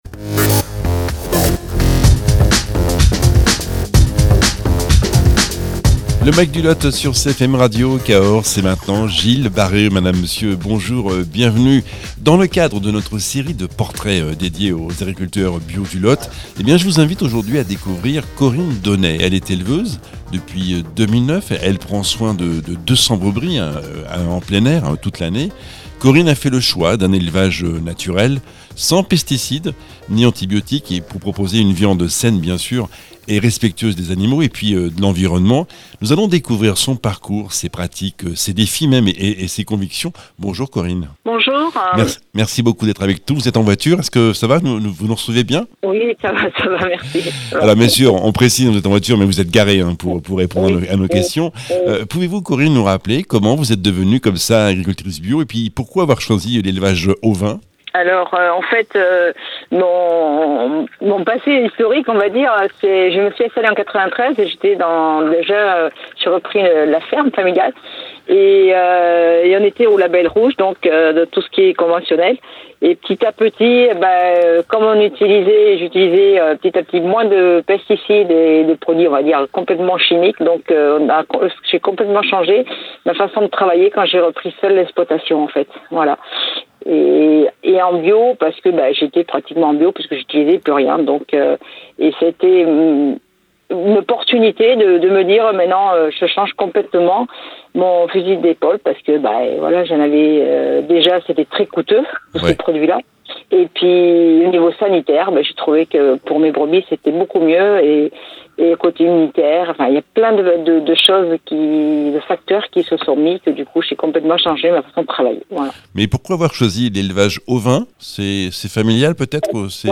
éleveuse d’ovins